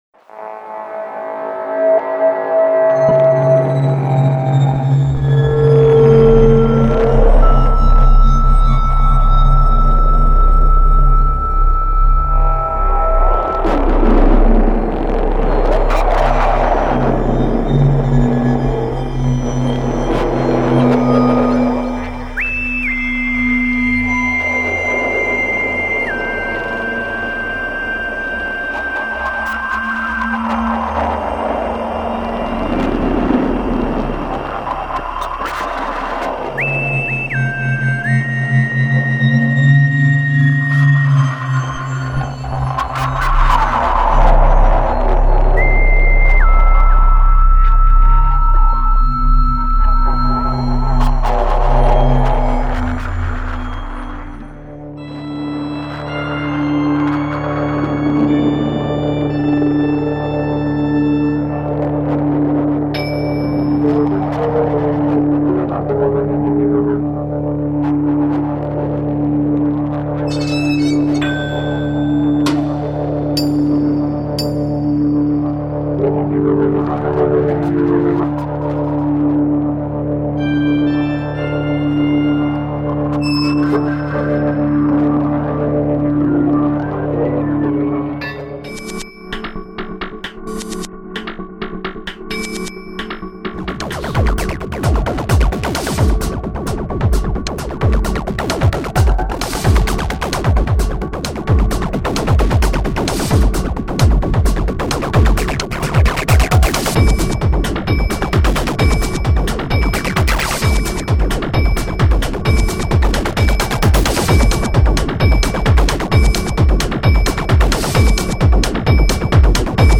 Hudba pro divadlo